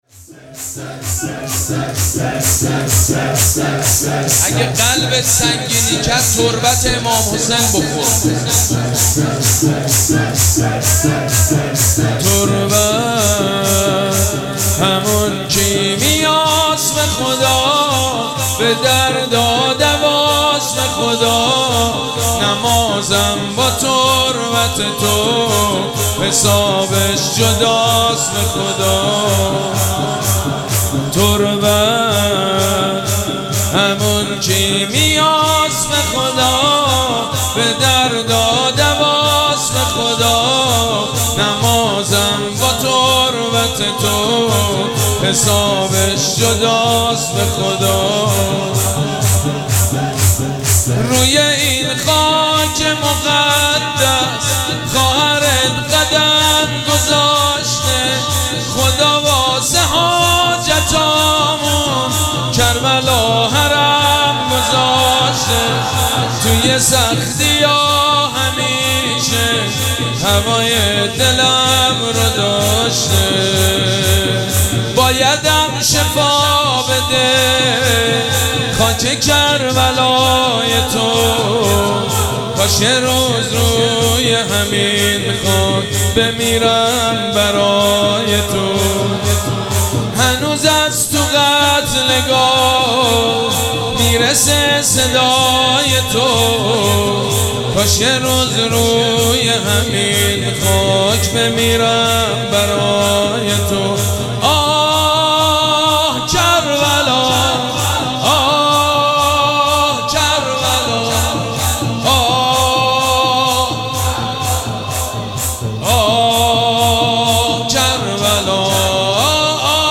مراسم عزاداری شام شهادت حضرت رقیه سلام الله علیها
شور
مداح
حاج سید مجید بنی فاطمه